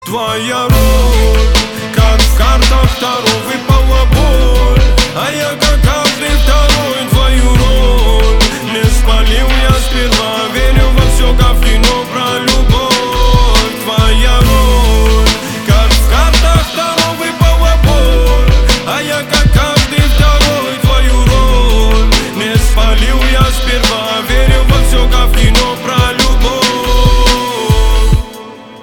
лирика
Хип-хоп
грустные
русский рэп
спокойные